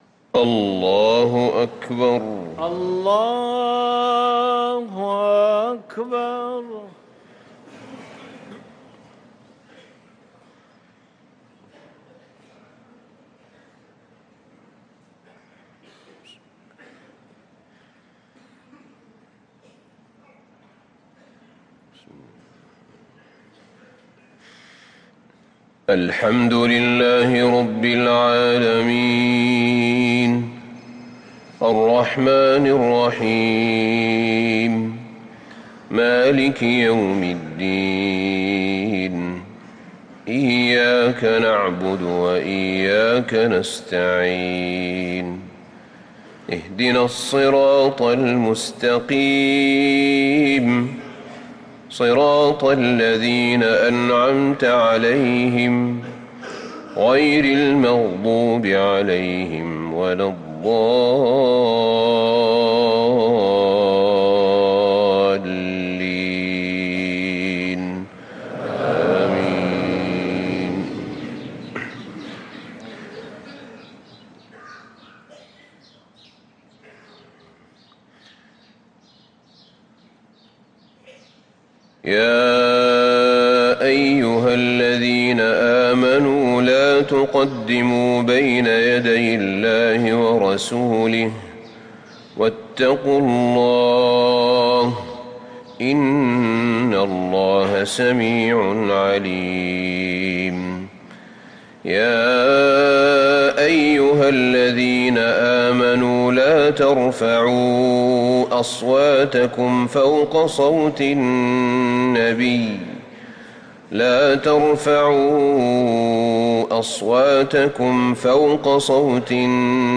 صلاة الفجر للقارئ أحمد بن طالب حميد 17 ذو الحجة 1444 هـ
تِلَاوَات الْحَرَمَيْن .